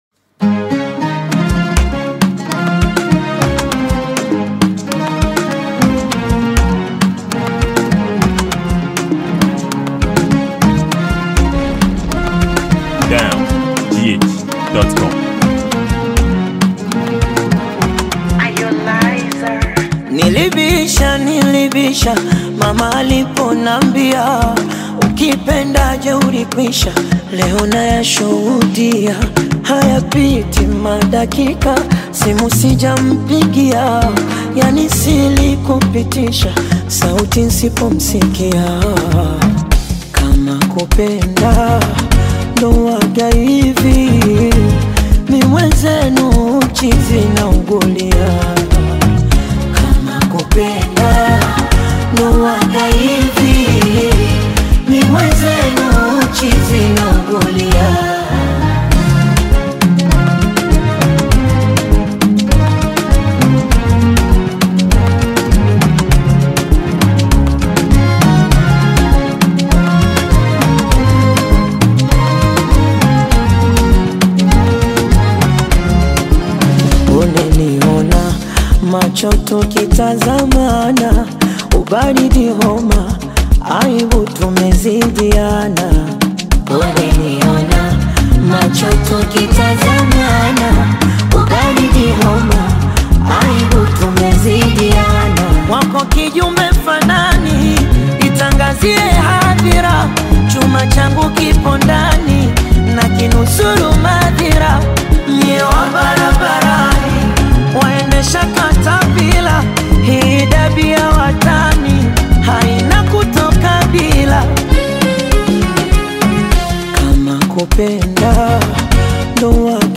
Afrobeat genre